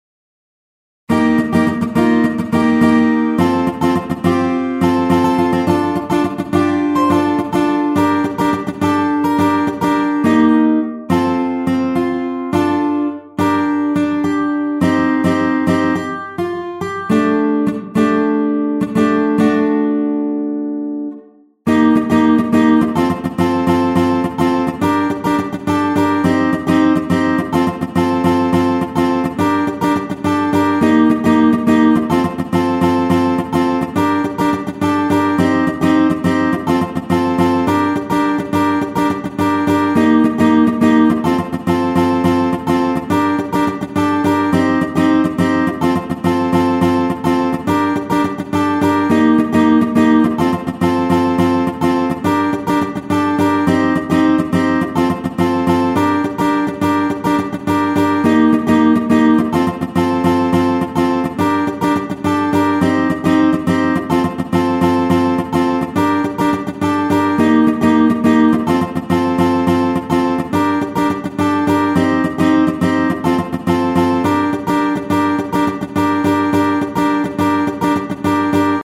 T Guitar